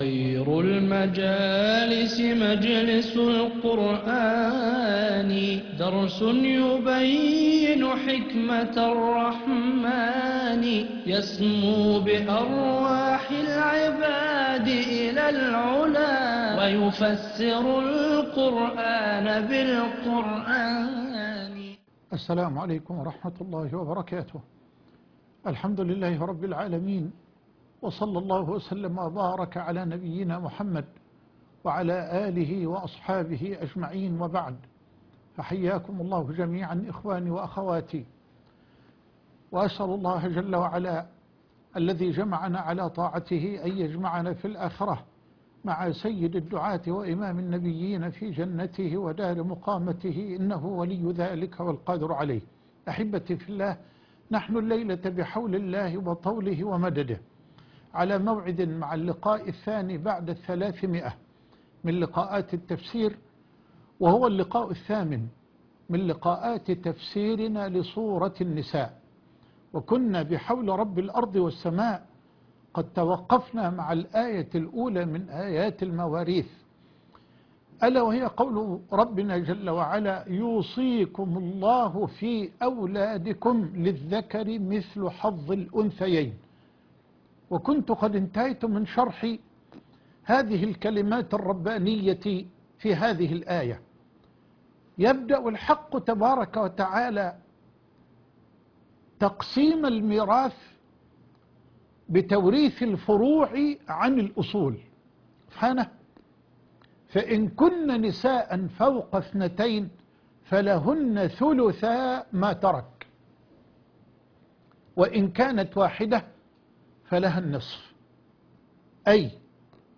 اللقاء 302تفسير الايه من 7 الى 9 سورة النساء ( 9/10/2022 ) التفسير - فضيلة الشيخ محمد حسان